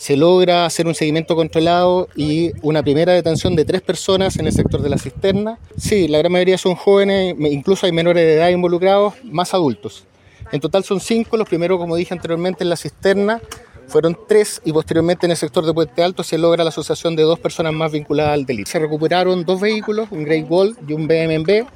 El coronel de la Prefectura Santiago Norte de Carabineros, Marcos Gutiérrez, entregó más antecedentes, señalando que hubo un seguimiento controlado.